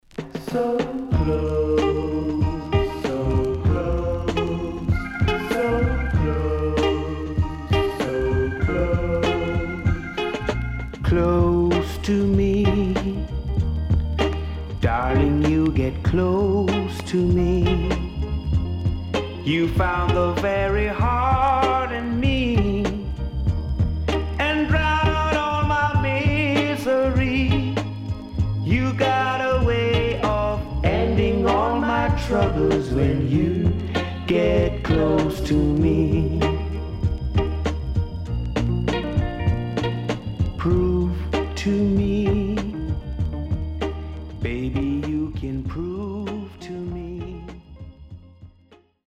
CONDITION SIDE A:VG(OK)
Good Ballad
SIDE A:所々チリノイズがあり、少しプチノイズ入ります。